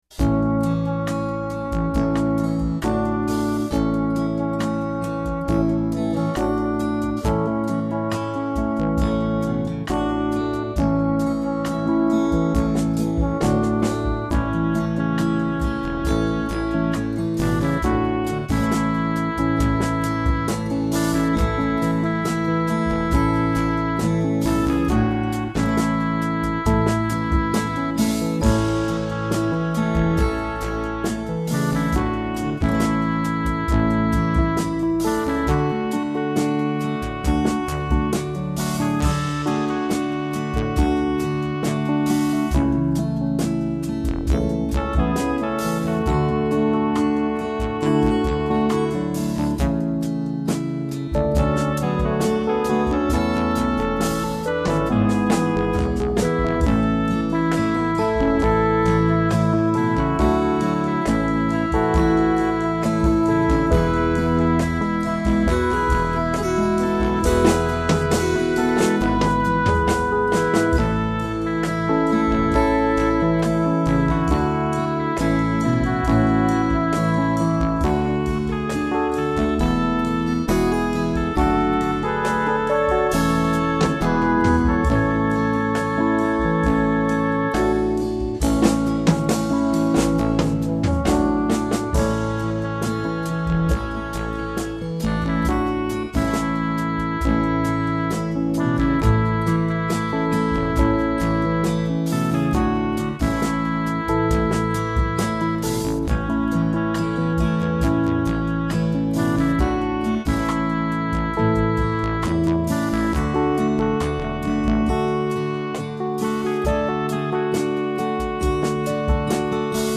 call and response from the cantor and the assembly